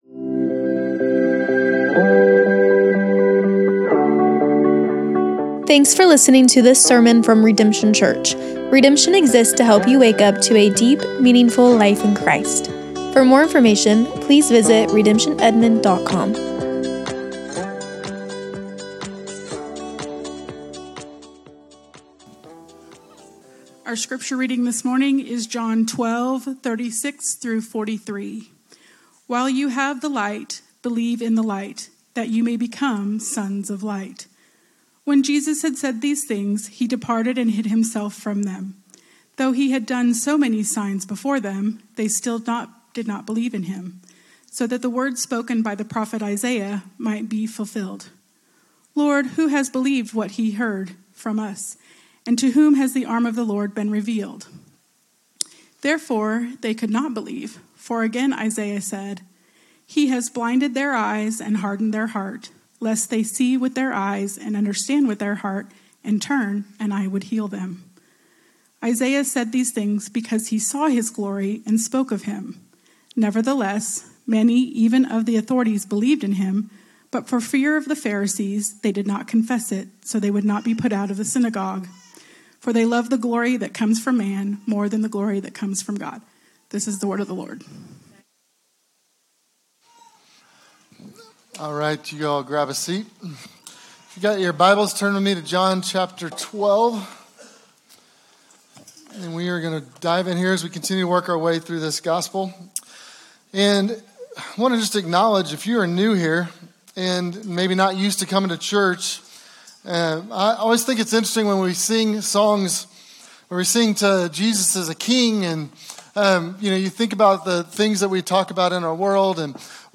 SERMONS - Redemption Church